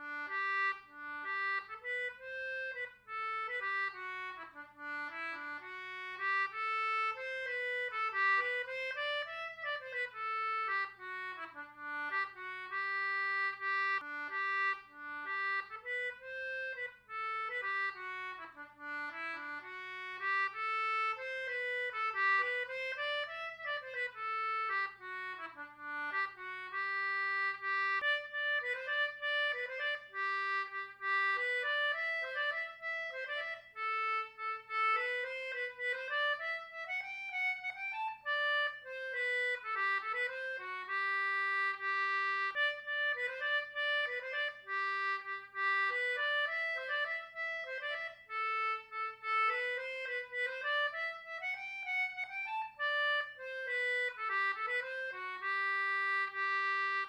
Most Celtic tunes were written for a specific instrument, such as the harp, flute, whistle, etc. The audio clips on this web site were played using English concertinas.
Fanny Powers Key G - Air